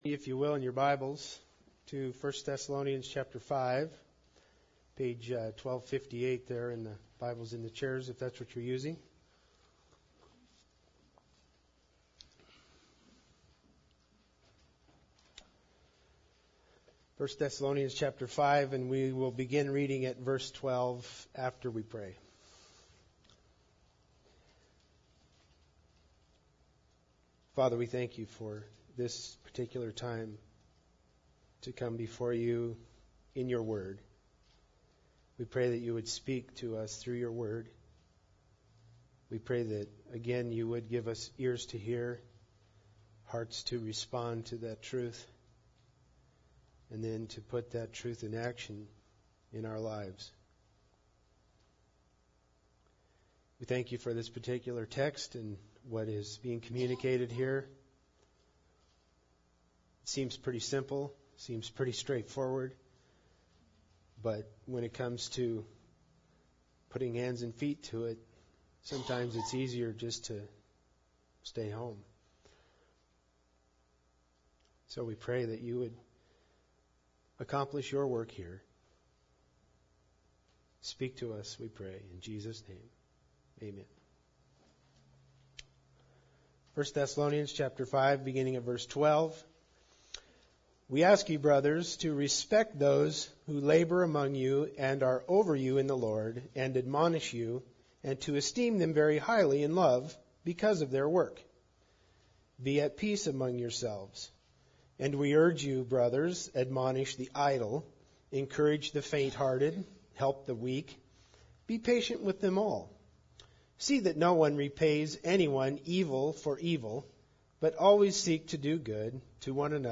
1 Thessalonians 5:16-18 Service Type: Sunday Service Bible Text